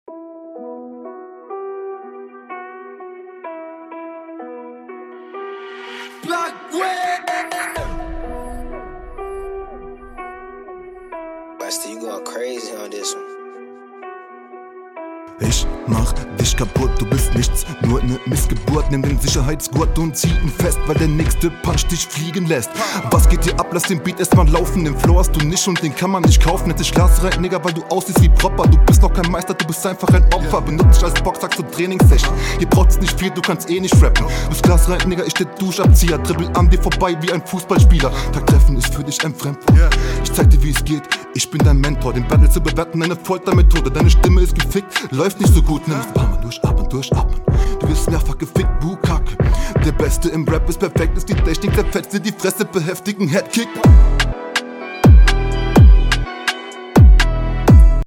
Soundqualität nicht super, aber sehr cool gerappt.